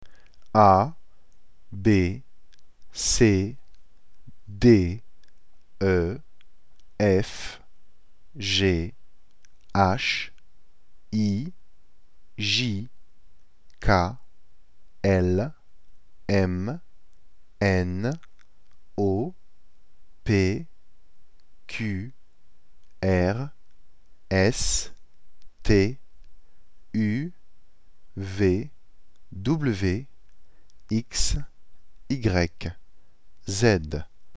فایل صوتی الفبای فرانسوی رو چندبار گوش می کنم تا به تلفظ و اسم حروف کمی مسلط بشم.
French-Alphabet-Audio.mp3